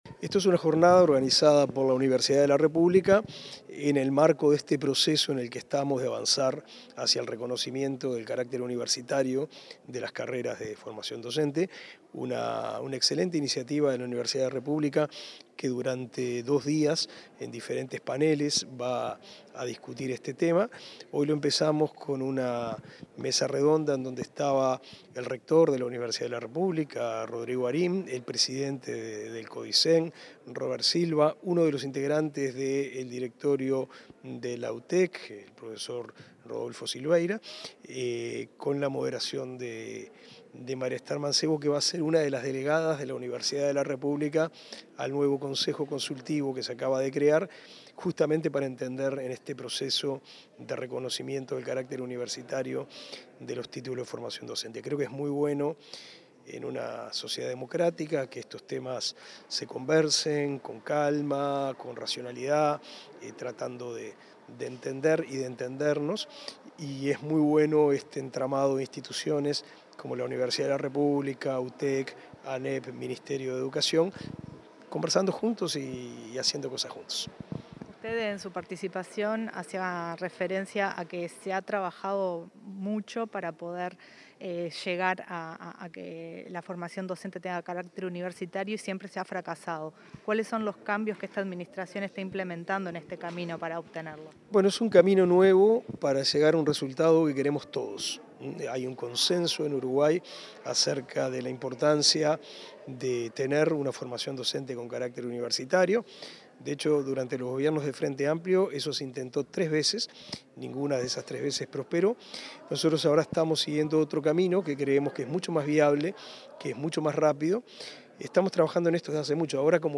Entrevista al ministro de Educación y Cultura, Pablo da Silveira
El ministro de Educación y Cultura, Pablo da Silveira, dialogó con Comunicación Presidencial luego de participar en una jornada de formación docente,